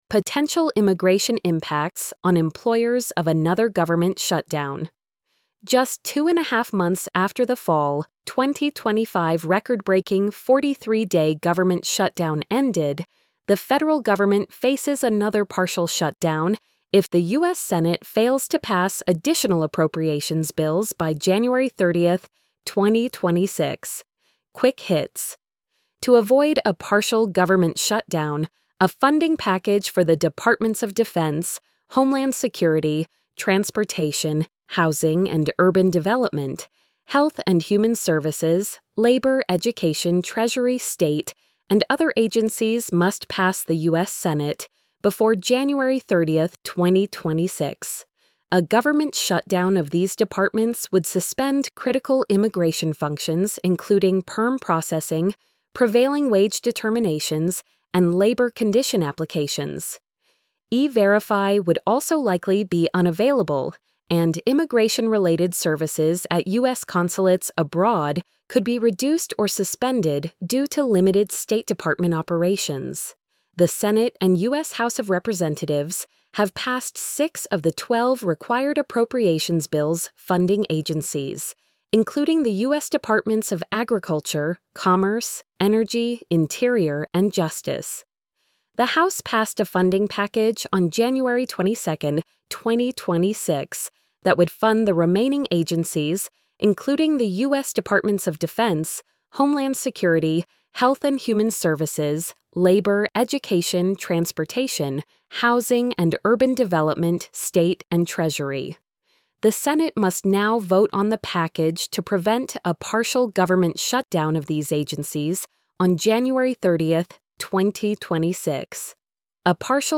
post-84875-tts.mp3